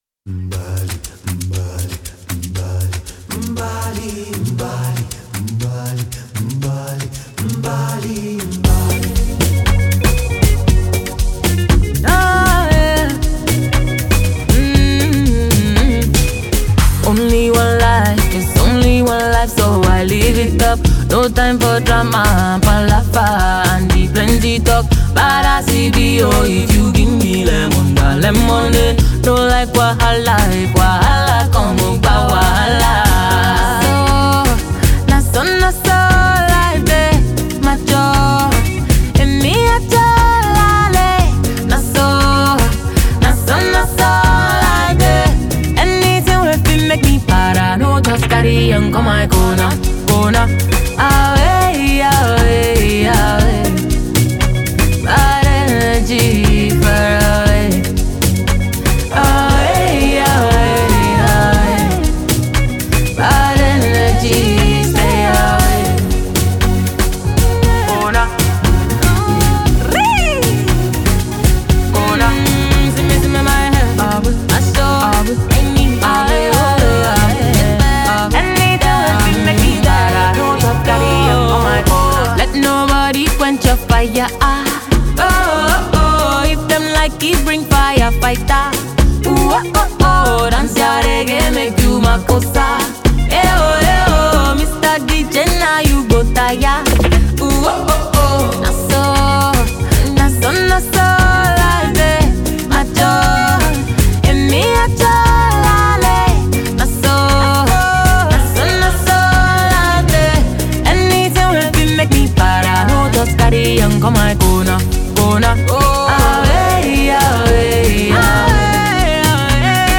and this is a solo tune.